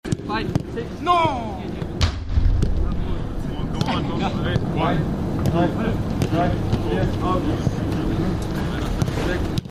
"Erste Halbzeit war schwer" I Pressekonferenz nach Bayern München - Eintracht